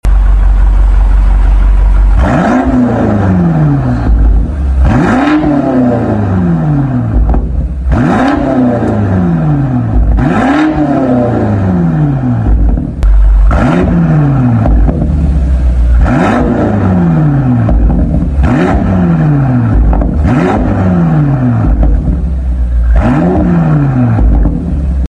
BMW X5M F95 RES Catback sound effects free download
BMW X5M F95 RES Catback Sound Test~~